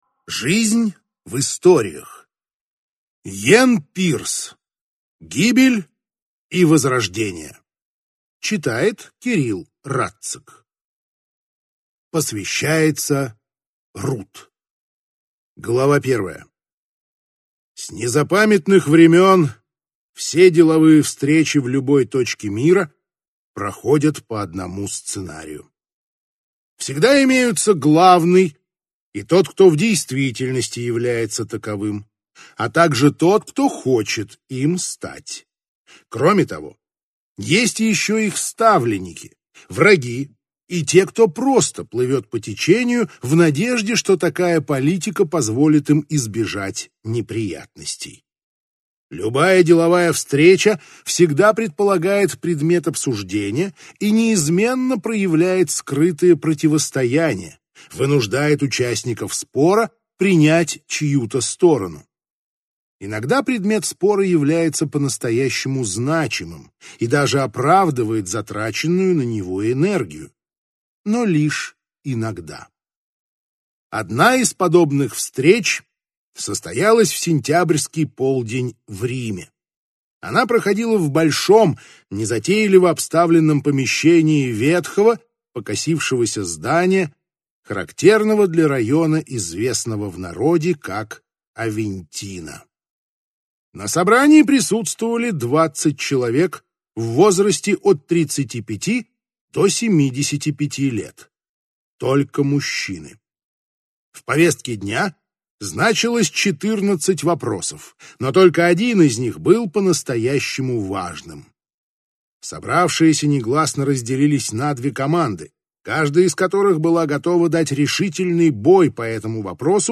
Аудиокнига Гибель и возрождение | Библиотека аудиокниг